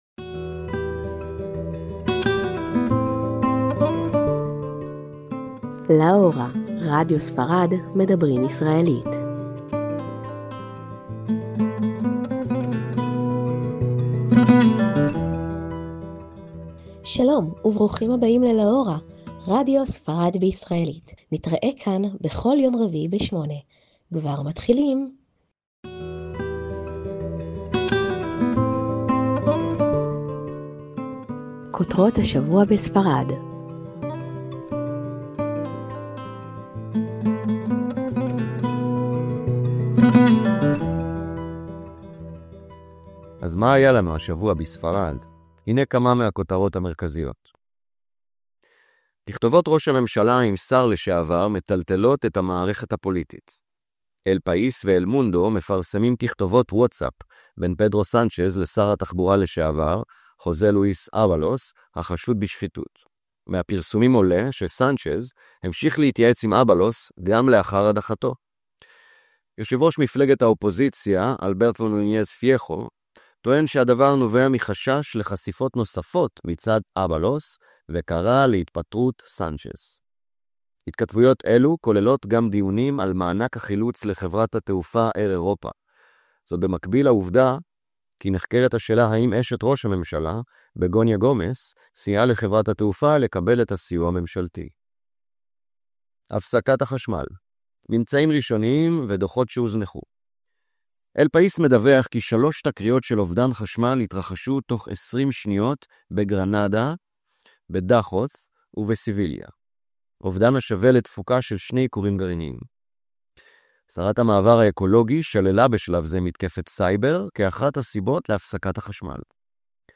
"LA HORA": MEDABRÍM ISRAELÍT - לה הורה״ – תכנית רדיו בעברית לטובת הישראלים בספרד"